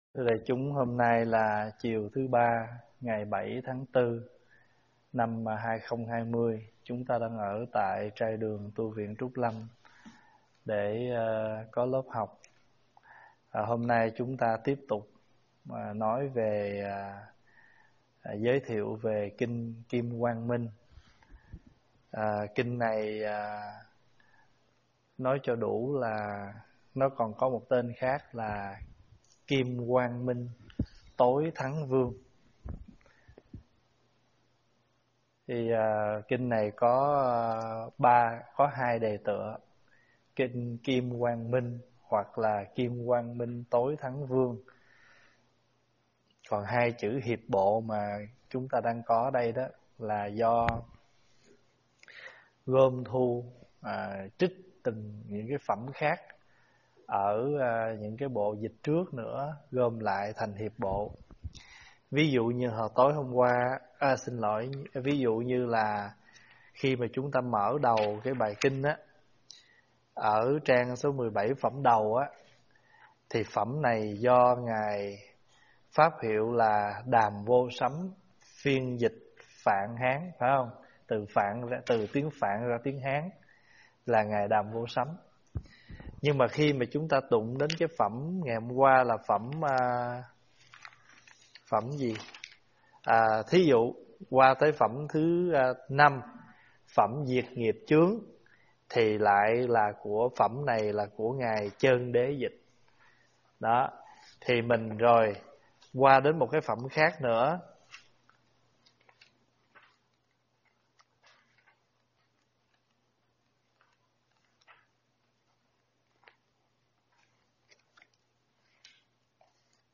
tại tv Trúc Lâm.